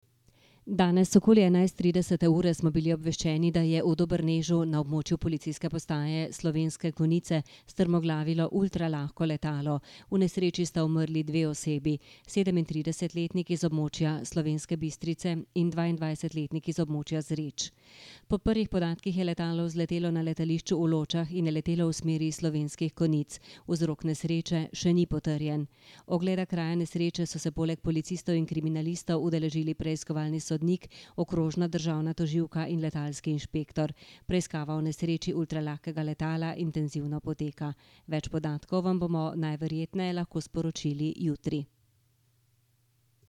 Zvočni zapis izjave o nesreči  (mp3)